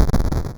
Explosion.wav